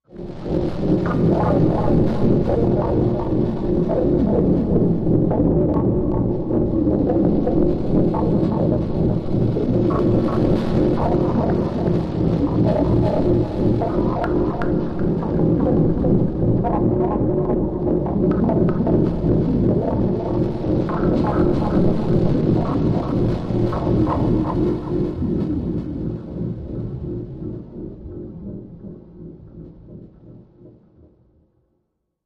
Critical Mass Electro Static Low Pulses Moving